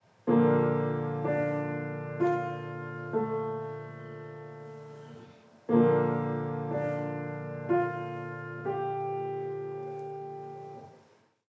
Threads of noise, tape loops, and samples.
You know it’s a good morning when you bump into a grand piano before your first coffee.